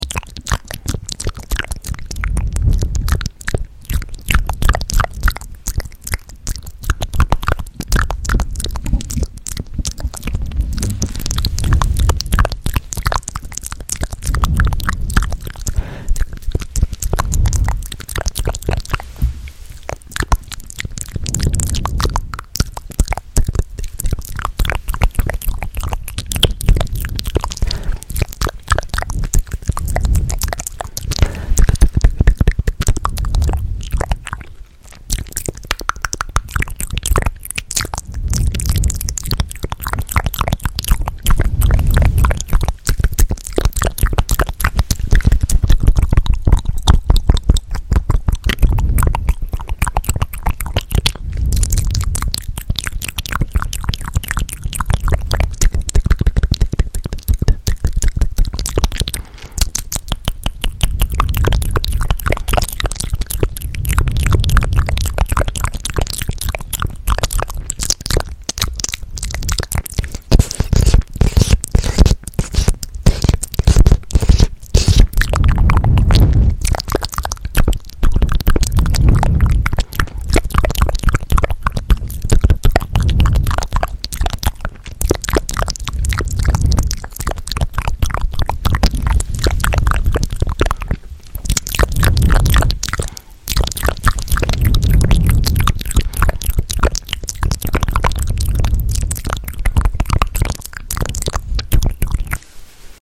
ASMR FRENCH MOUTH SOUNDS 🇫🇷 Sound Effects Free Download